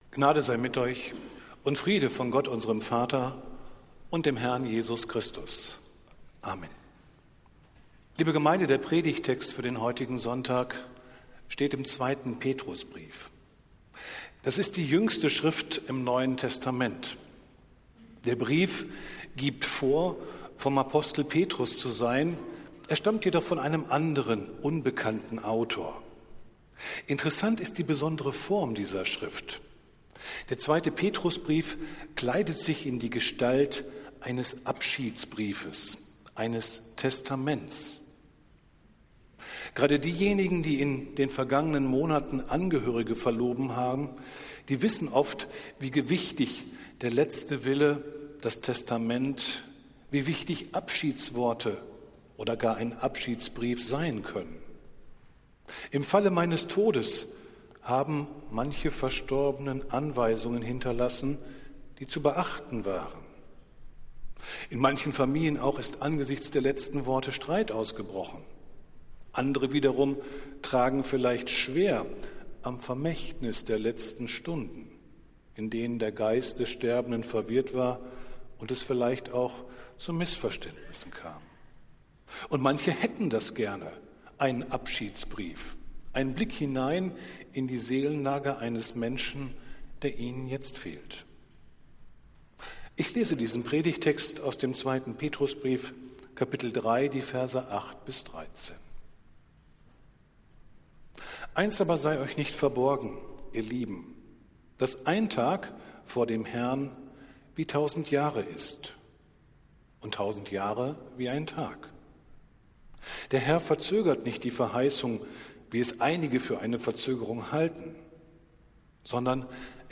Predigt des Gottesdienstes aus der Zionskirche am Totensonntag, den 26. November 2023